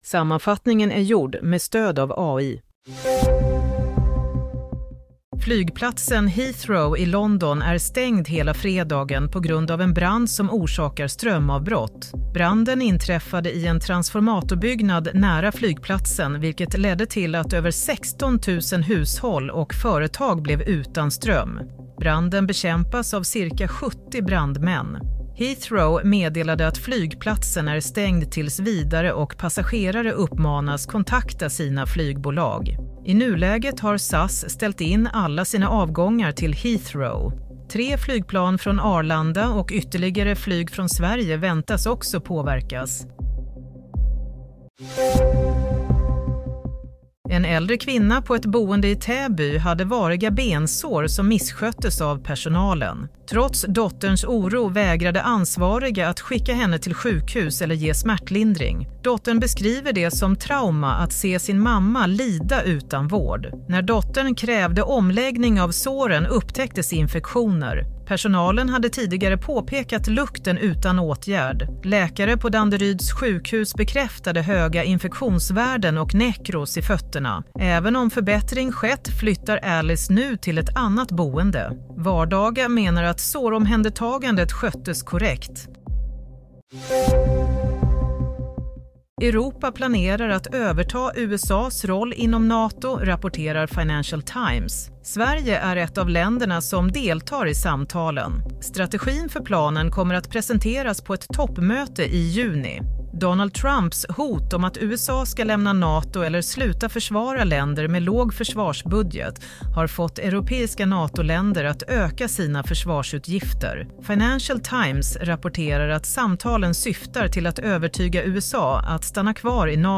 Play - Nyhetssammanfattning 21 mars 07.30